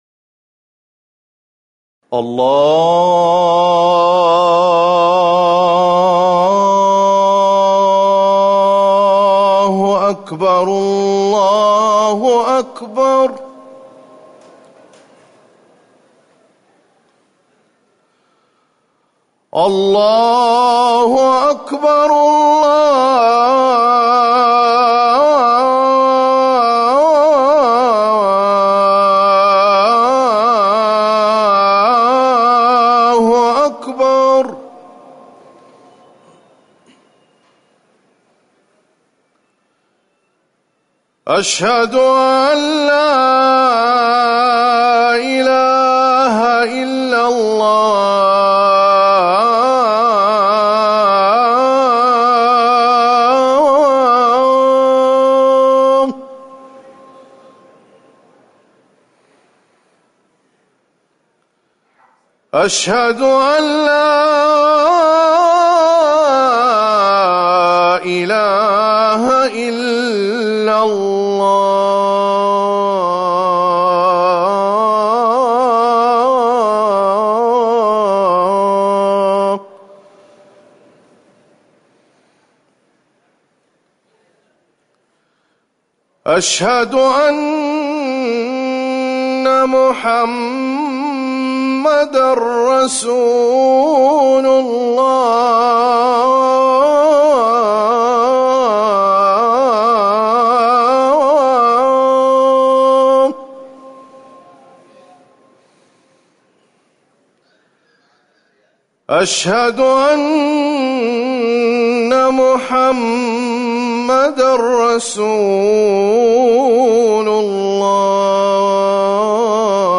أذان المغرب - الموقع الرسمي لرئاسة الشؤون الدينية بالمسجد النبوي والمسجد الحرام
تاريخ النشر ٢١ محرم ١٤٤١ هـ المكان: المسجد النبوي الشيخ